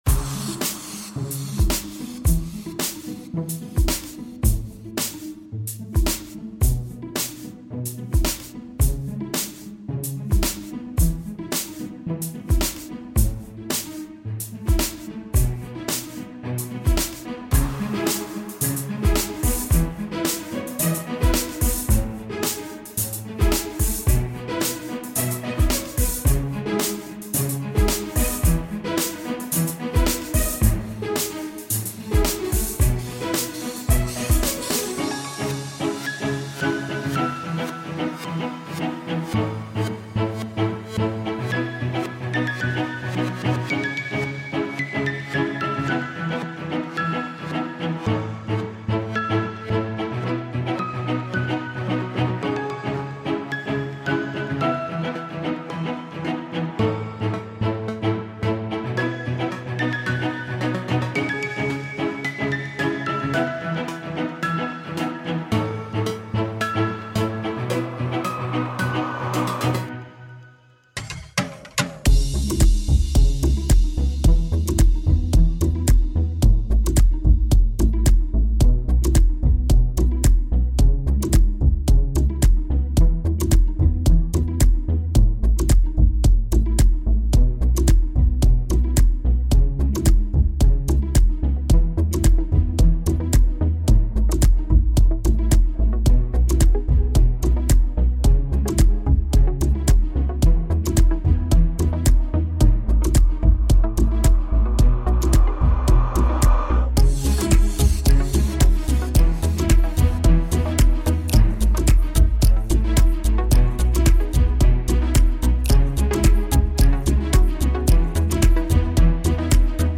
Enjoy some deep house yes!
=P Lay back and relax to this chill mix!